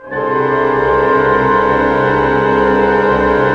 Index of /90_sSampleCDs/Roland - String Master Series/ORC_ChordCluster/ORC_12-Tone